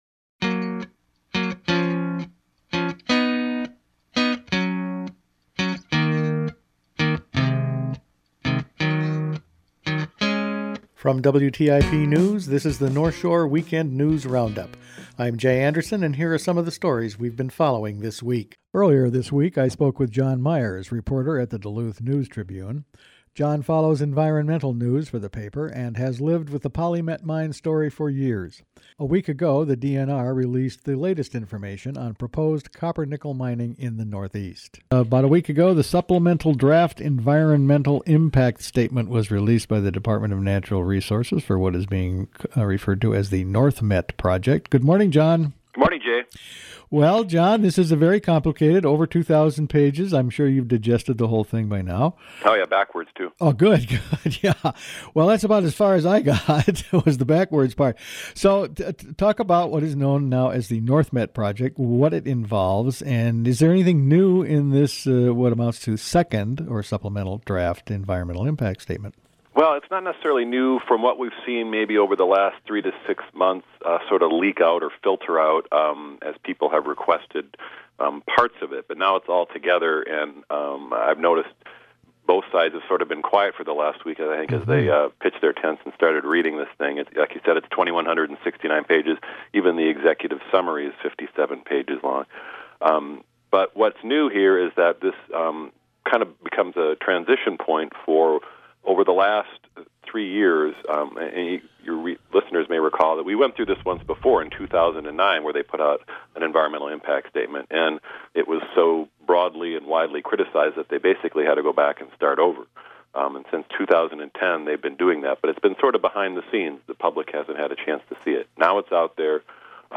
Attachment Size WrapFinalCut.mp3 23.51 MB Each week the WTIP news staff puts together a roundup of the news over the past five days. Last week the DNR released an environmental plan from PolyMet for copper-nickel mining.